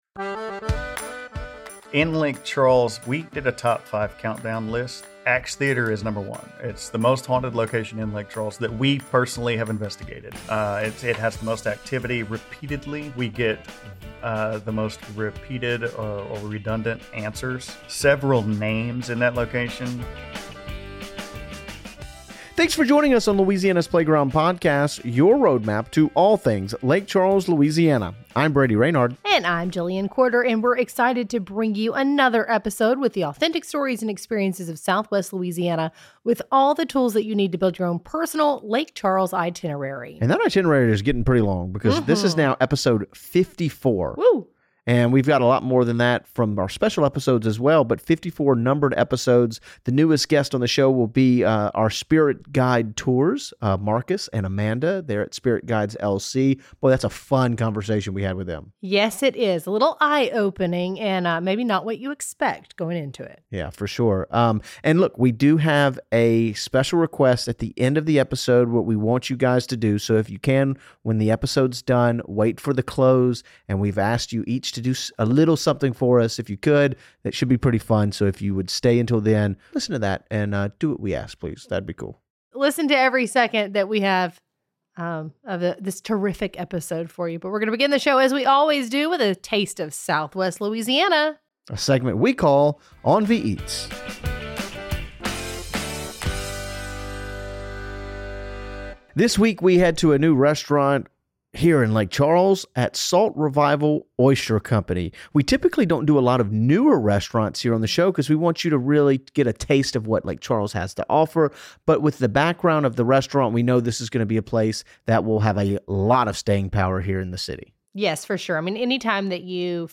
The conversation has many twists and turns from paranormal experiences to philosophical and theoretical discussions!